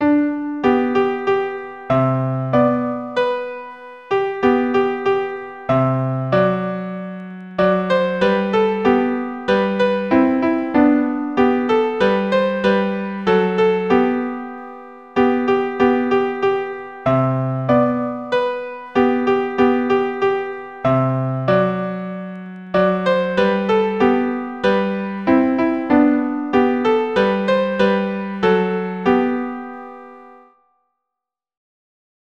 Piano / Keyboard Chords in keyboard view.